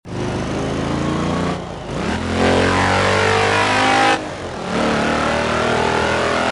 upshift.mp3